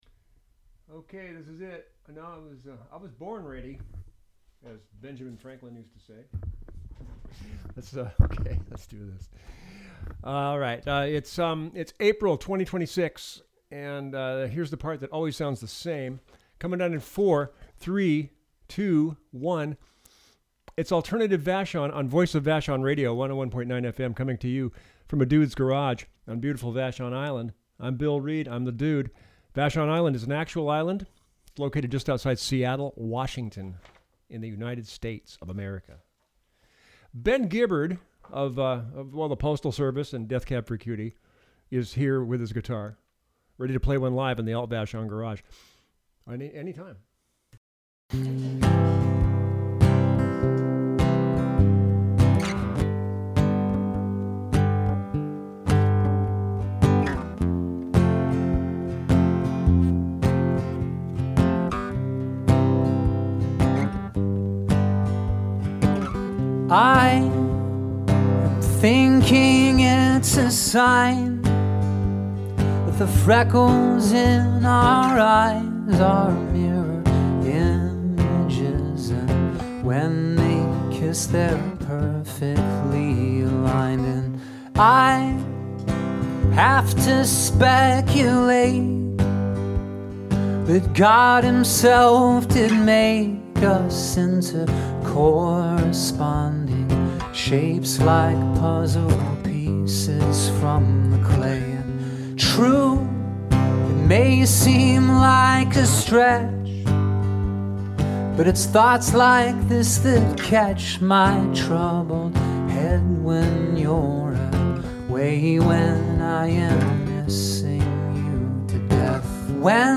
Classic Alternative Rock